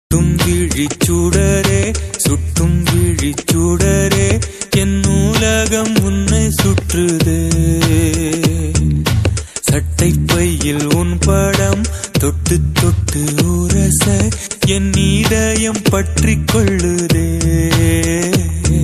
Timeless Tamil romantic classical-melody hook tone